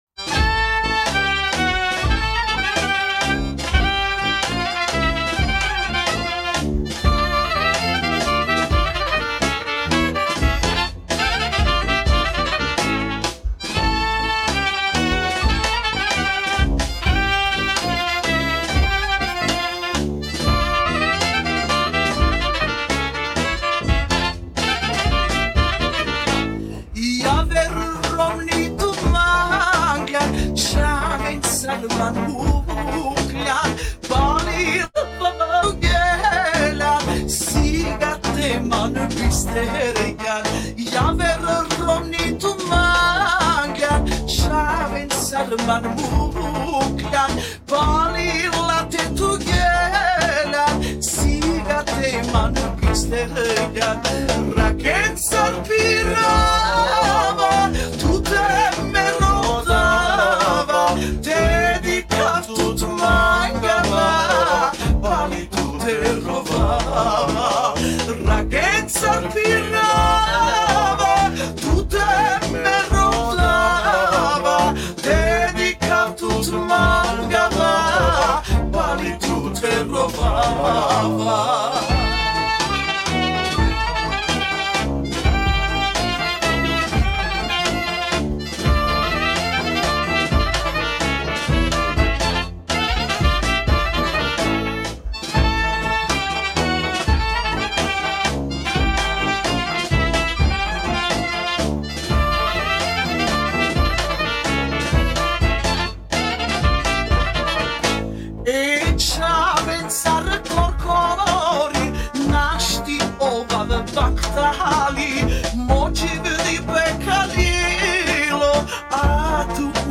transposed down to A